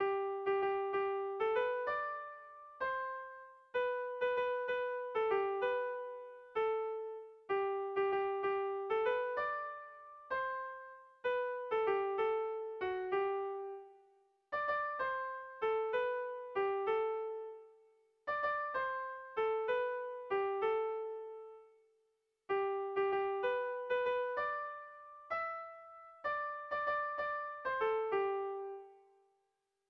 Kontakizunezkoa
A1A2A3